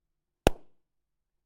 拟声词 " Pop Balloon 3
描述：一个气球弹出